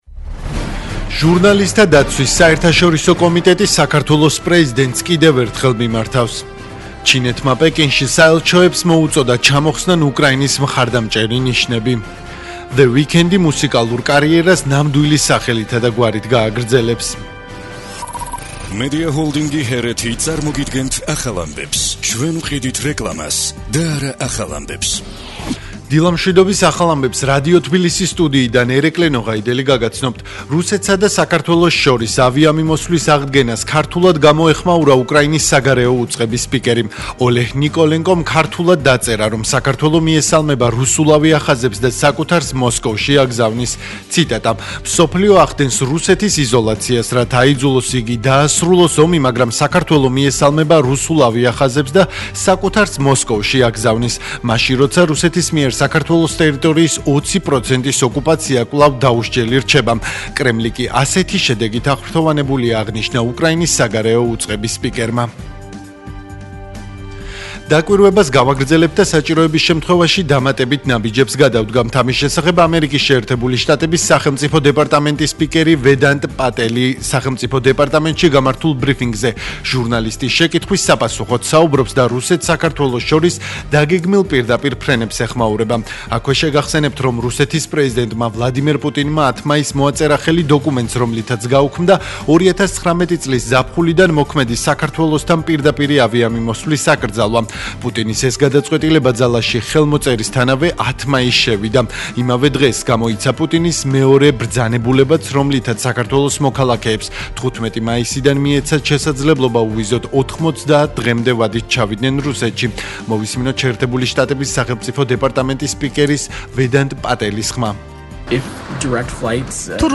ახალი ამბები 11:00 საათზე